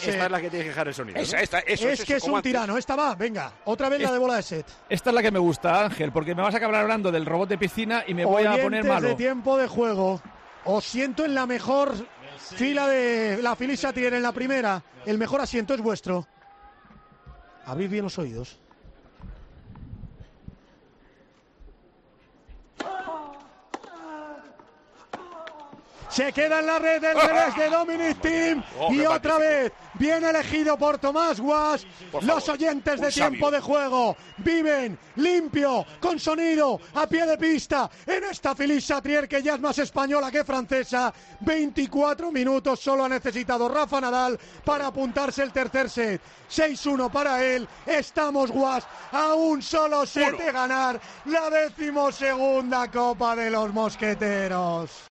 COPE, desde la cabina de la pista central de Roland Garros, es testigo de la final entre Rafa Nadal y Dominic Thiem.